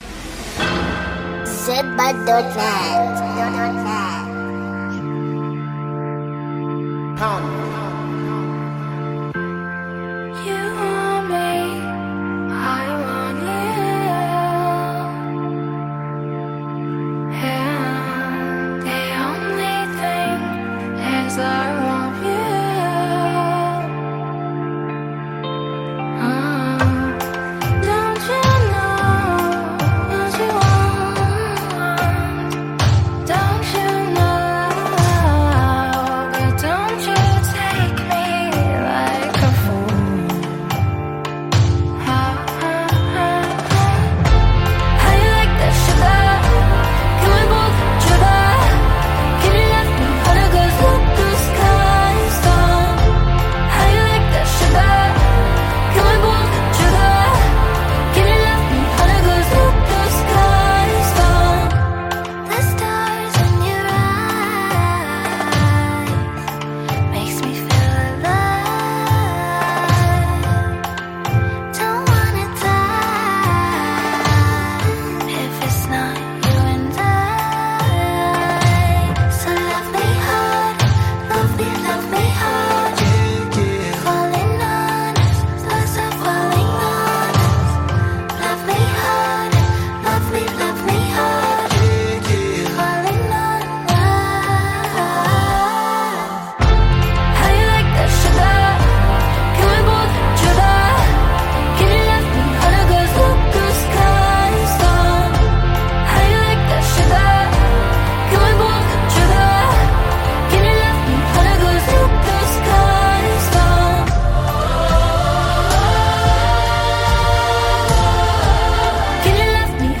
b>'s voice is smooth and blends well with the music.